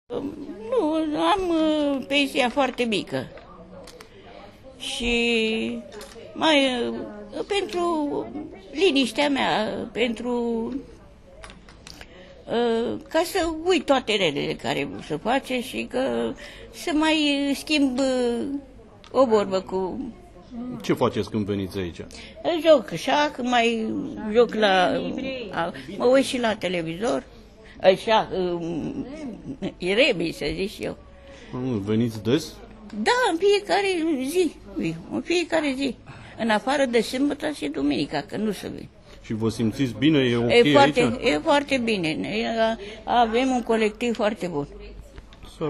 Am stat de vorbă si cu doi beneficiari ai acestui centru, care mi-au spus că vin zilnic aici, pentru a nu se mai simți singuri, pentru a socializa cu ceilalți beneficiari și cu personalul centrului și, nu in ultimul rând, pentru a servi masa de prânz.